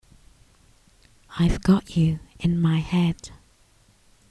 描述：循环合成器112bpm
Tag: 和弦 循环 rompler